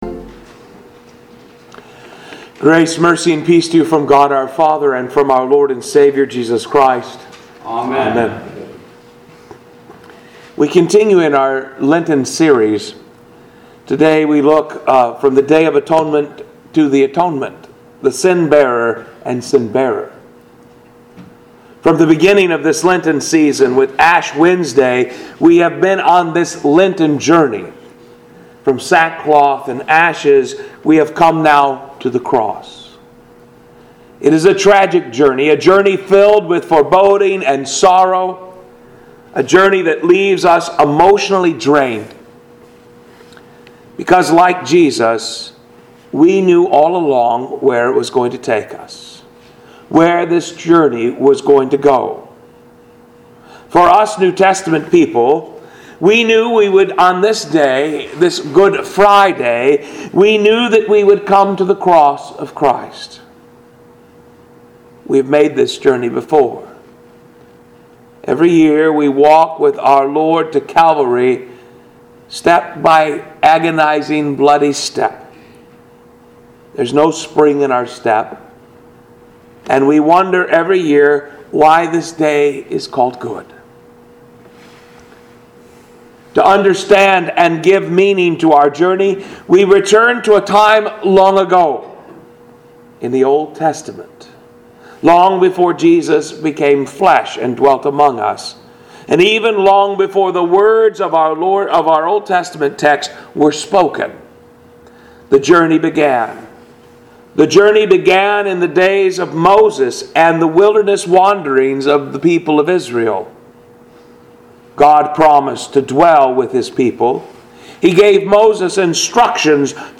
Pilgrim Ev. Lutheran Church - Audio Sermons